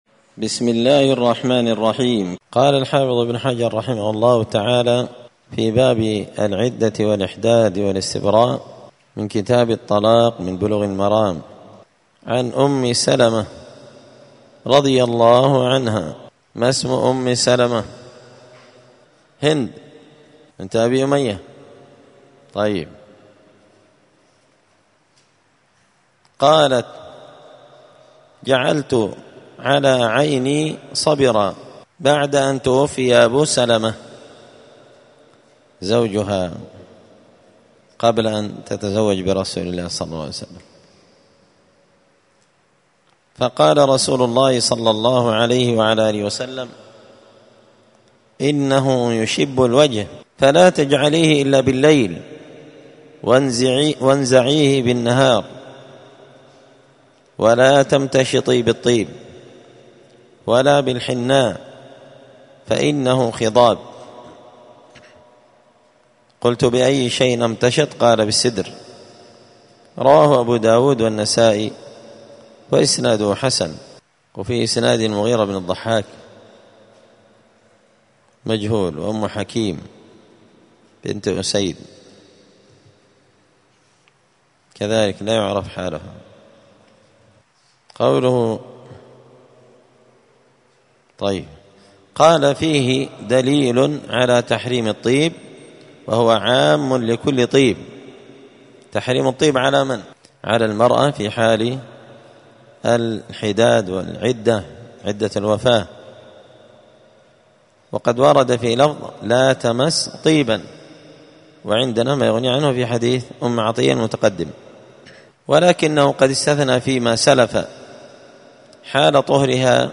*الدرس الحداي والعشرون (21) {تابع لباب العدة الإحداد والاستبراء}*